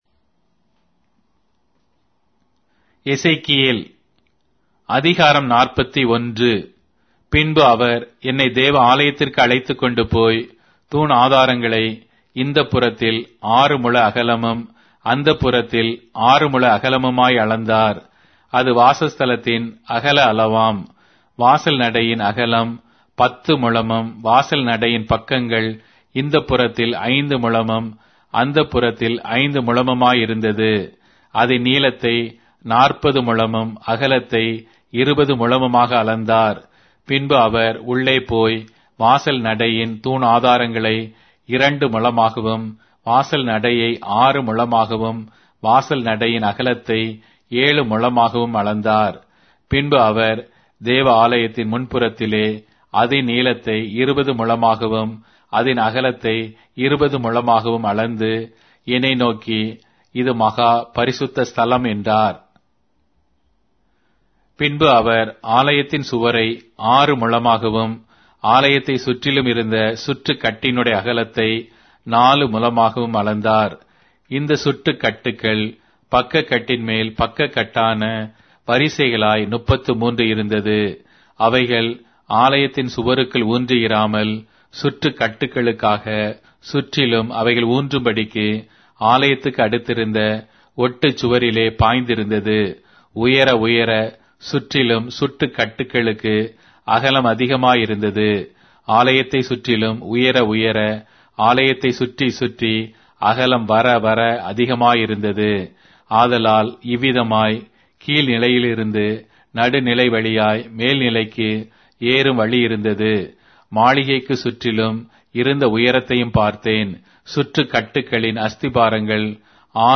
Tamil Audio Bible - Ezekiel 7 in Irvte bible version